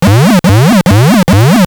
trhq_alarm.wav